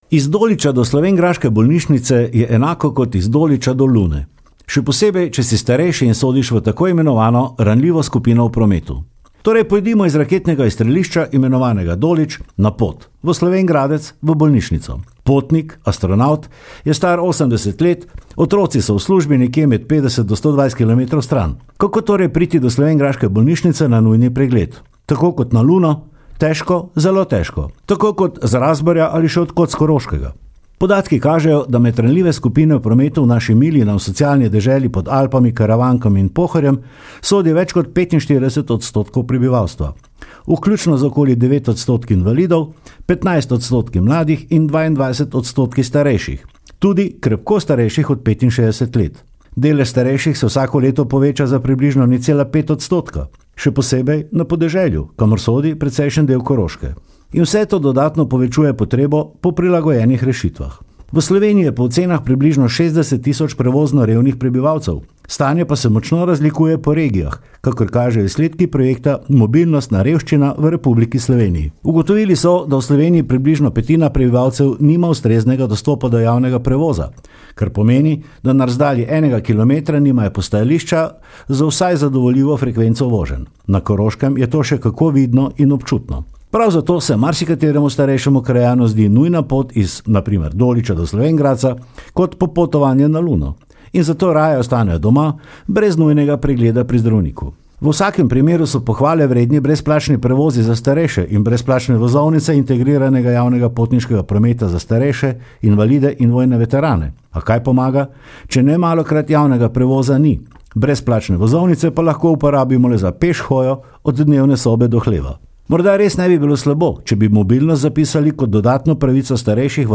Komentar je stališče avtorja in ne nujno tudi uredništva